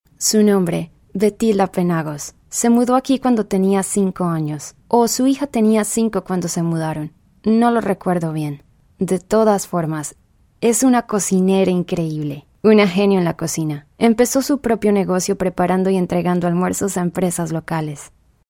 locutora de español neutro